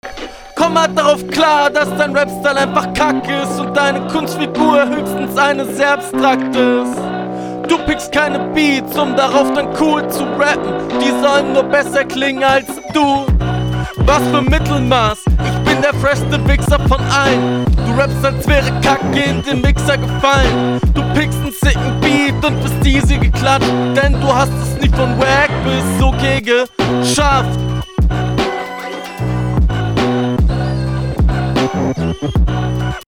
Yo, viele Sachen gekontert und auf fast alles eingegangen aber dein Flow unterliegt ganz klar …
Flowlich, Reimtechnisch und stimmlich kommst du leider nicht an die heran.